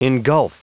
Prononciation du mot engulf en anglais (fichier audio)